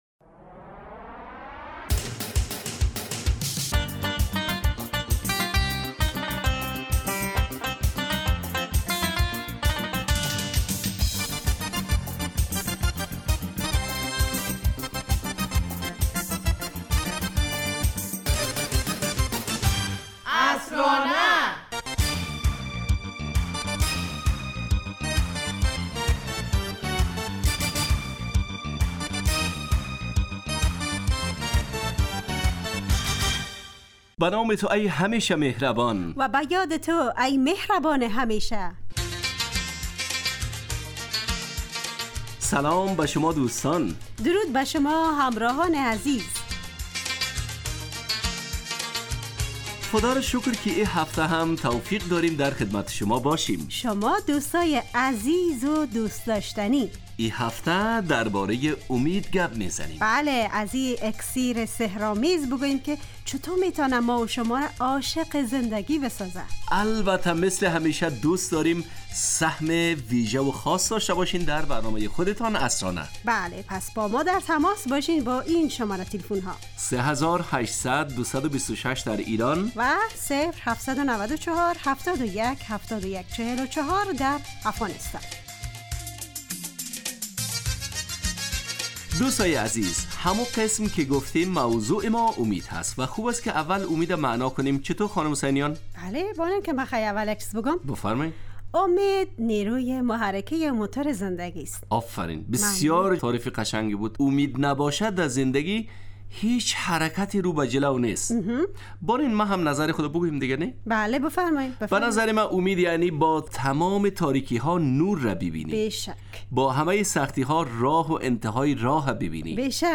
عصرانه برنامه ایست ترکیبی نمایشی که عصرهای جمعه بمدت 35 دقیقه در ساعت 17:25 دقیقه به وقت افغانستان پخش می شود و هرهفته به یکی از موضوعات اجتماعی و فرهنگی مرتبط با جامعه افغانستان می پردازد.